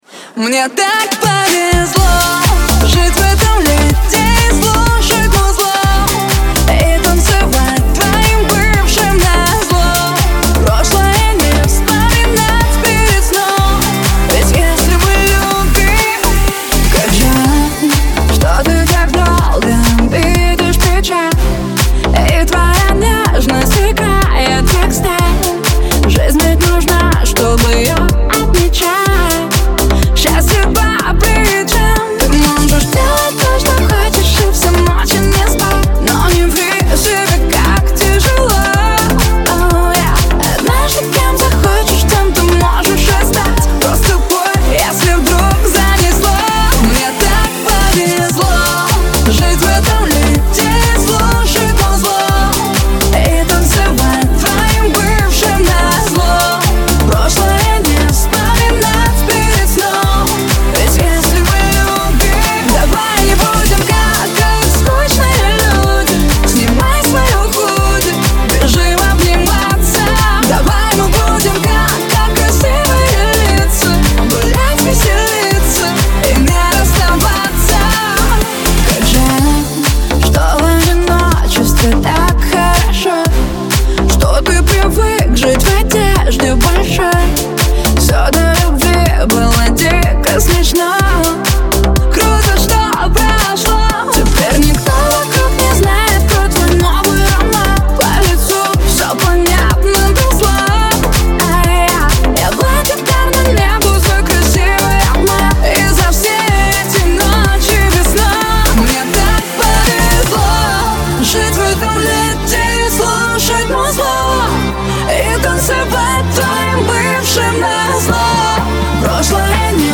Трек размещён в разделе Клубная музыка | Ремиксы.